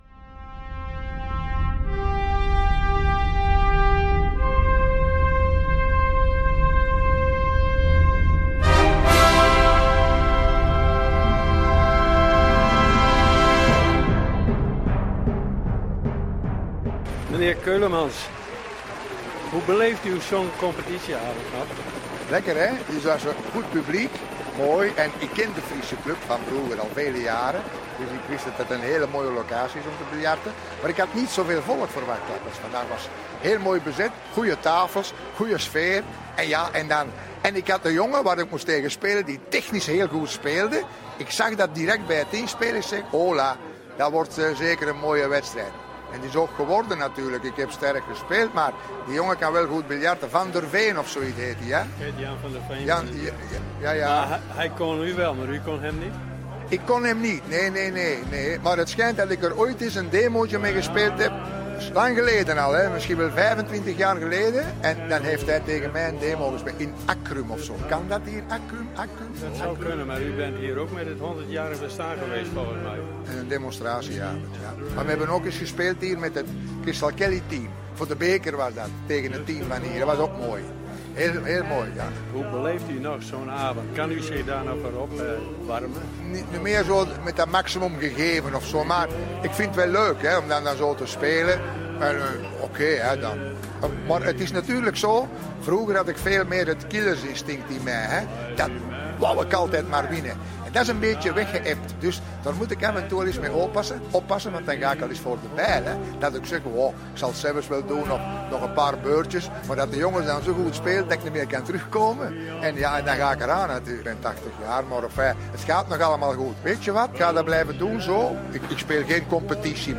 In de Friesche Club.